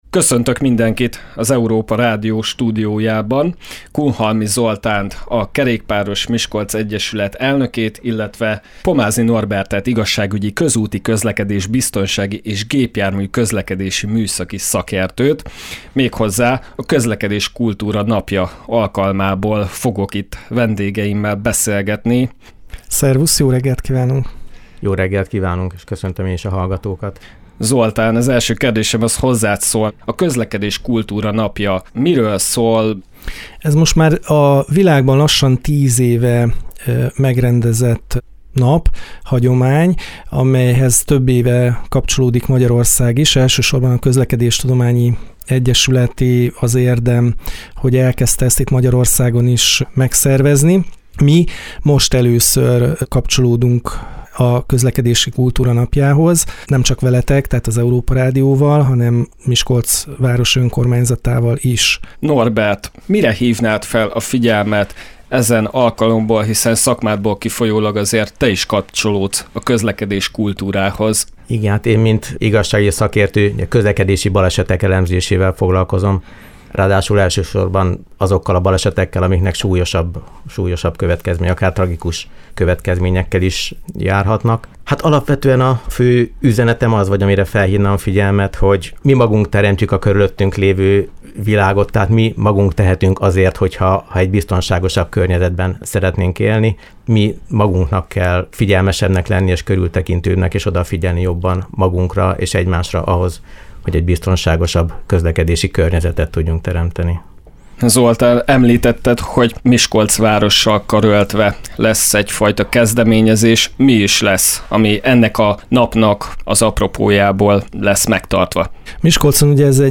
A városban a Kerékpáros Miskolc Egyeület és az önkormányzat közös kezdeményezéssel hívja fel a figyelmet a közlekedési kultúrára. A mostani riporttal csatlakozott rádiónk is a kerékpáros egyesülettel karöltve ehhez az eseményhez.